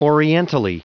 Prononciation du mot orientally en anglais (fichier audio)
Prononciation du mot : orientally